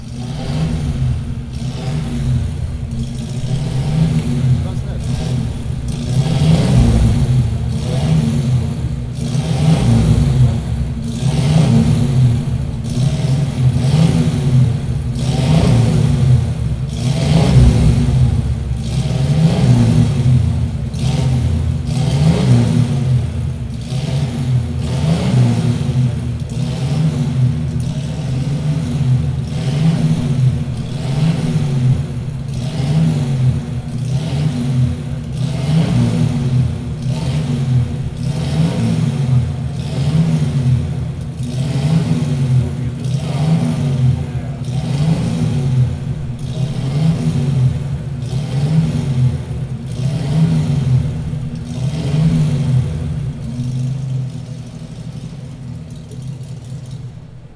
Porsche_356-1.mp3